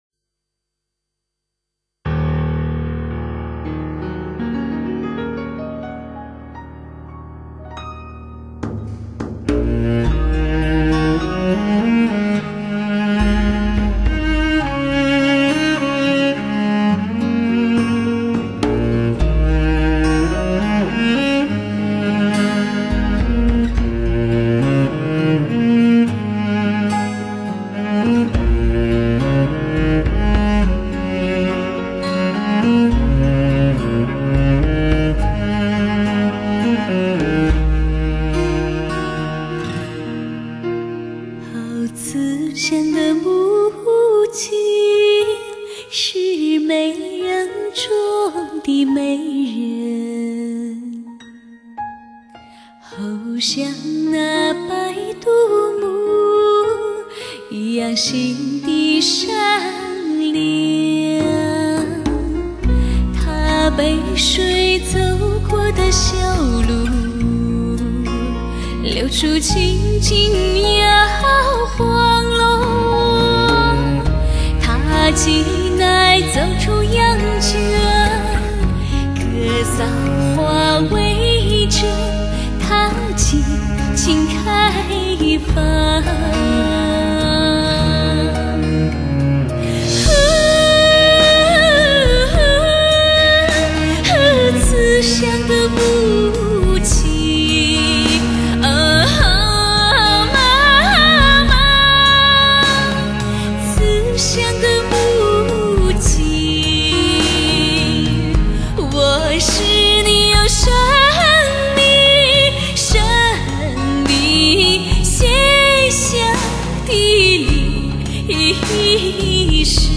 曲调如潺潺的泉水般舒畅